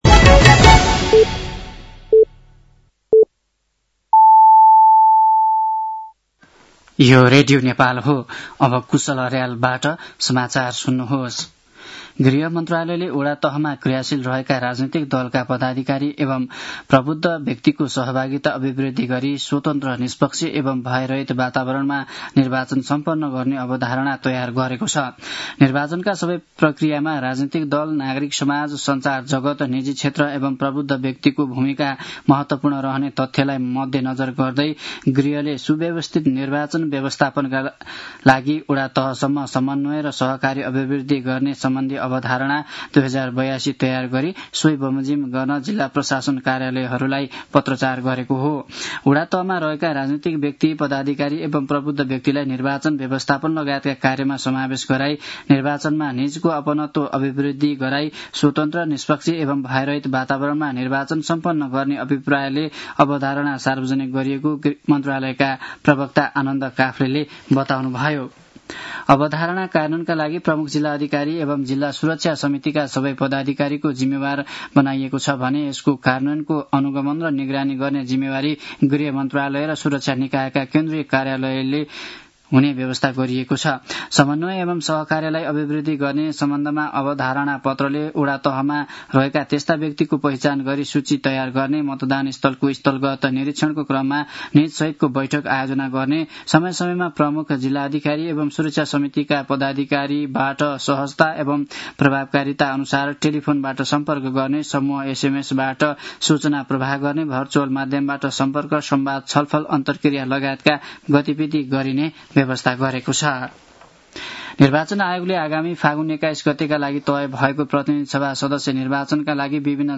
साँझ ५ बजेको नेपाली समाचार : १६ माघ , २०८२
5-pm-nepali-news-10-16.mp3